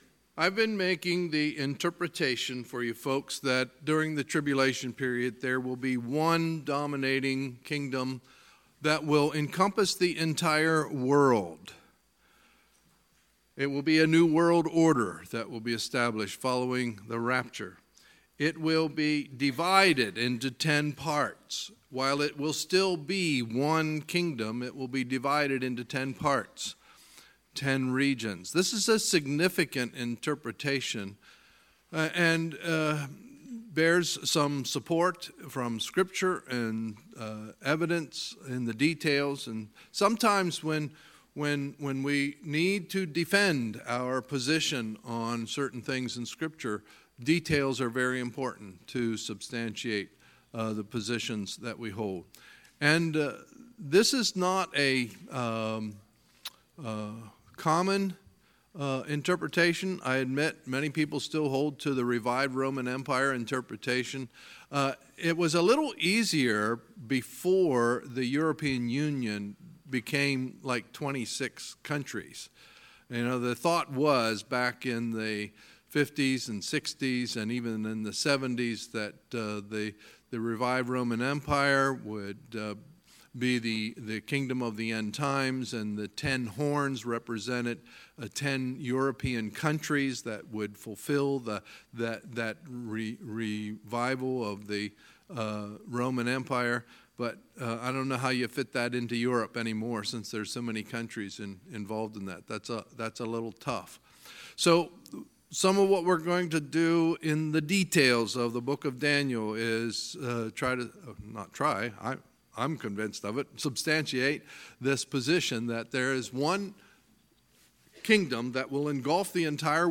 Sunday, August 12, 2018 – Sunday Evening Service
Sermons